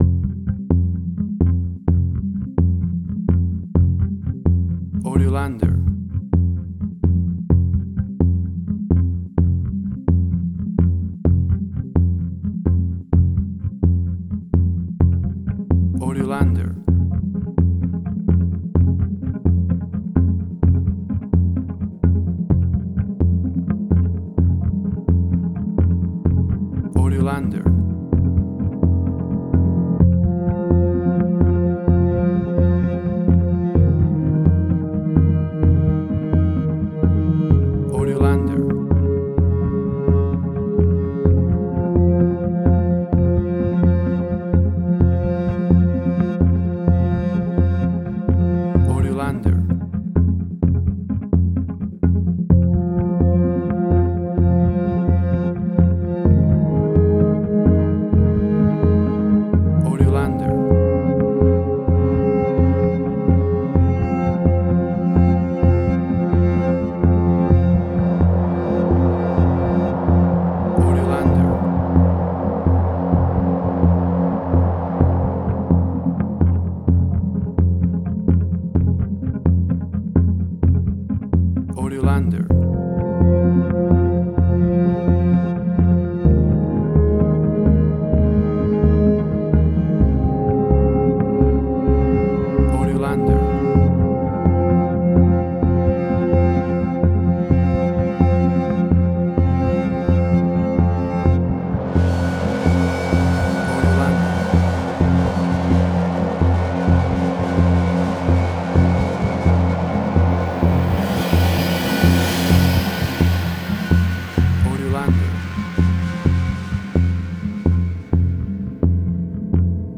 Post-Electronic.
Tempo (BPM): 64